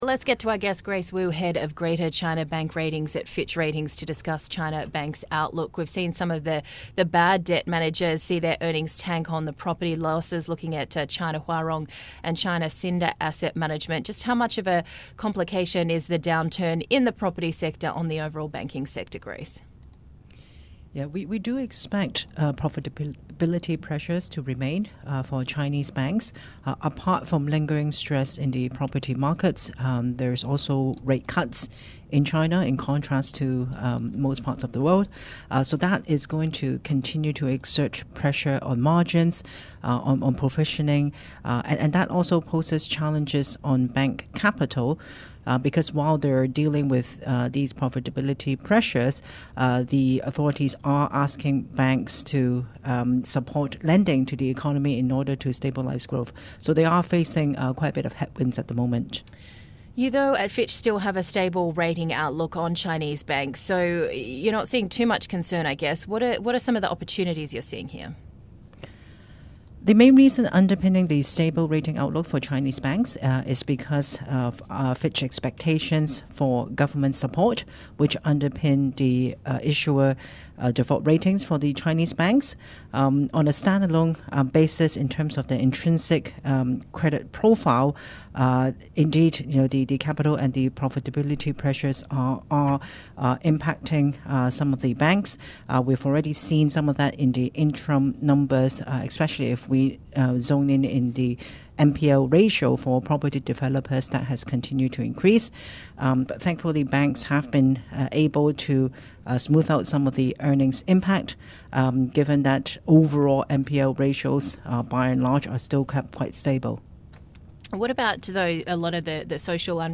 (Radio) - Bloomberg Daybreak: Asia Edition